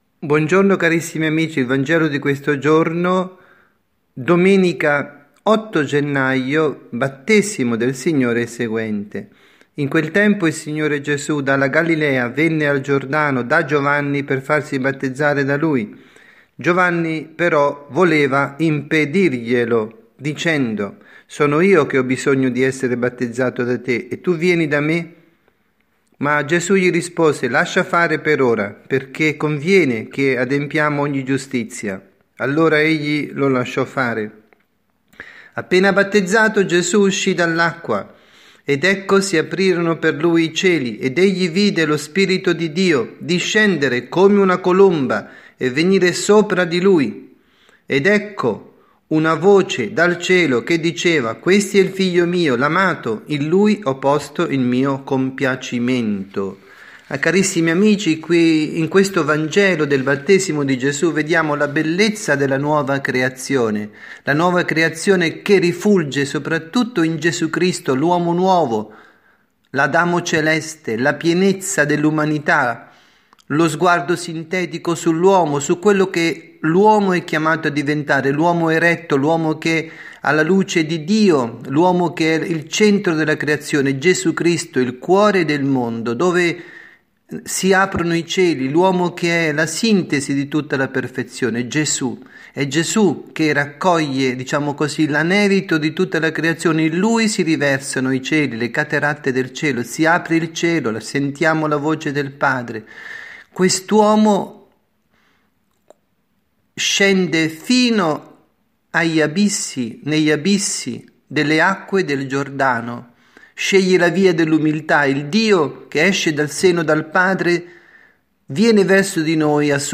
Omelia
dalla Parrocchia S. Rita, Milano